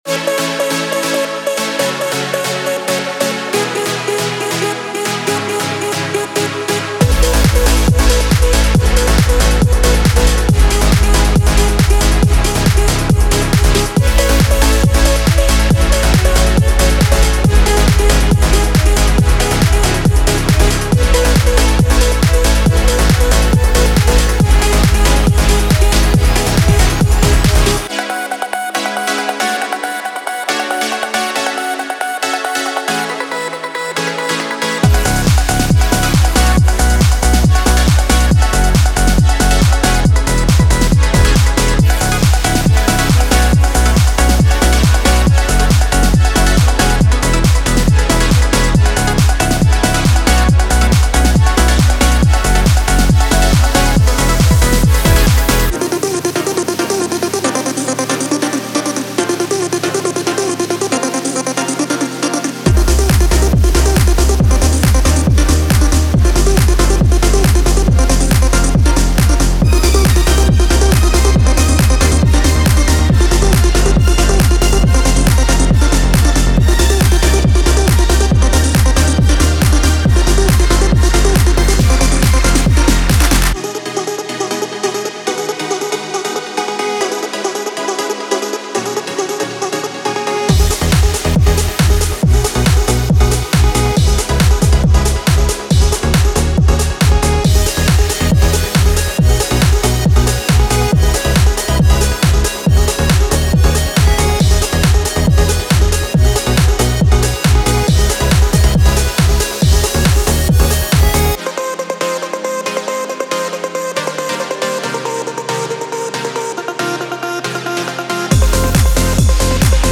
15 Bass Loops: Punchy and groovy basslines that provide the essential drive and low-end energy typical of 90s dance music.
30 Drum Loops: A diverse array of drum loops featuring classic 90s beats, from booming kick drums to crisp snares and hi-hats, to keep your tracks moving.
30 Synth Loops: Bright, melodic synth loops that deliver the signature sounds of the 90s, from catchy hooks to lush pads and arpeggiated sequences.
13 Vox and FX Loops: A blend of vocal chops, effects, and atmospheres to add character and depth to your productions, bringing that authentic 90s vibe to life.